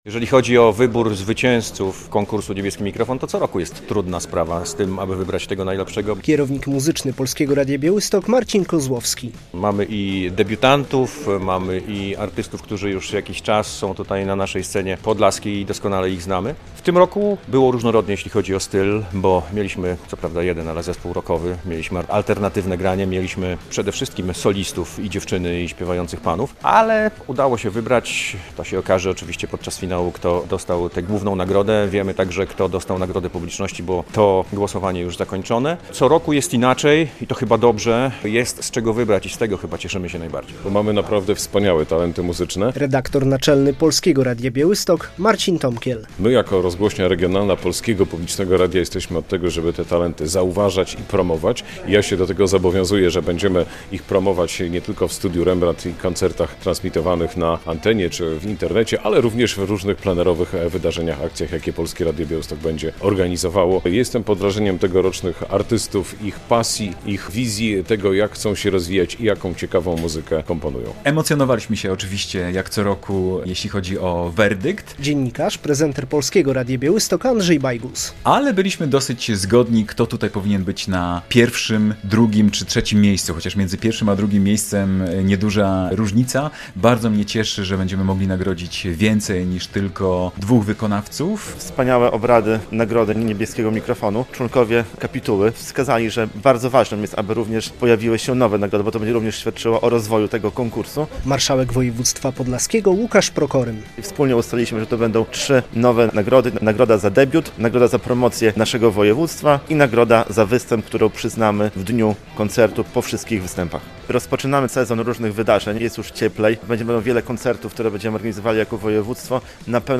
Jury wyłoniło laureatów Niebieskiego Mikrofonu 2024 - Muzycznej Nagrody Polskiego Radia Białystok - relacja